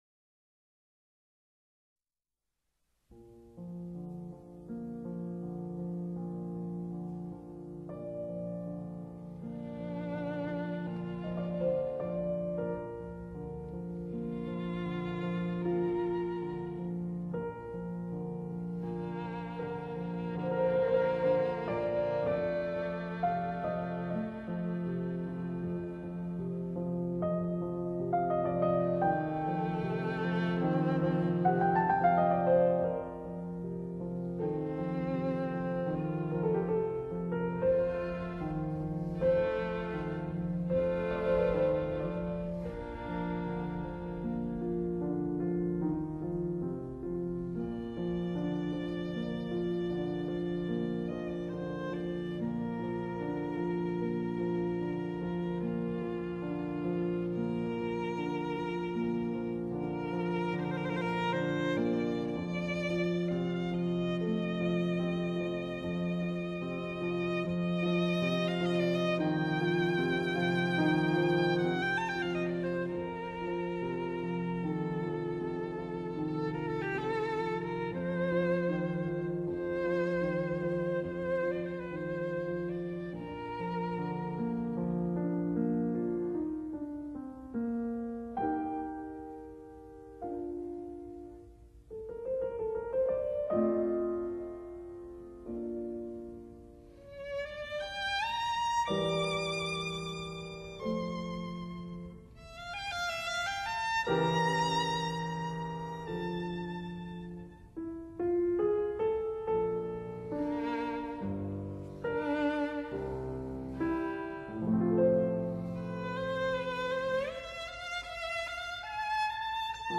فهرست: ده آهنگ از بهترین آهنگ‌های بی‌کلام بهاری از موسیقی جهان
02. Beethoven - Spring Sonata (Violin Sonata No. 5)_1.mp3